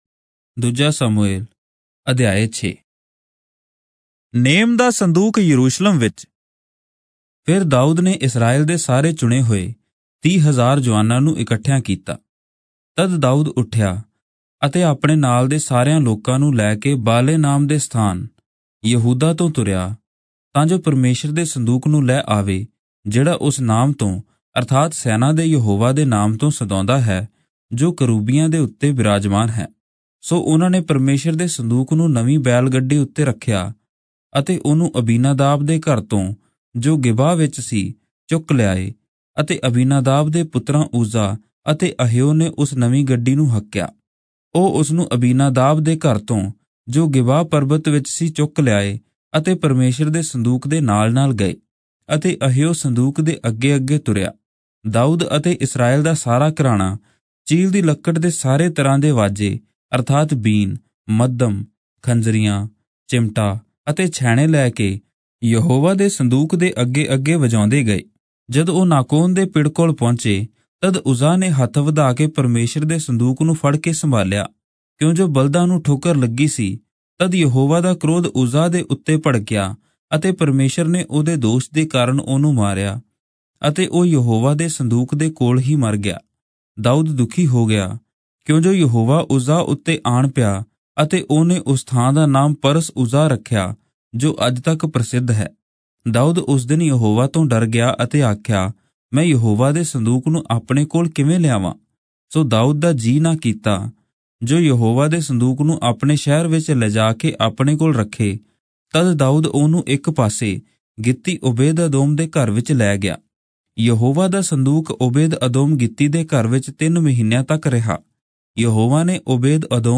Punjabi Audio Bible - 2-Samuel 23 in Irvpa bible version